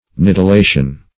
Search Result for " nidulation" : The Collaborative International Dictionary of English v.0.48: Nidulation \Nid`u*la"tion\, n. The time of remaining in the nest.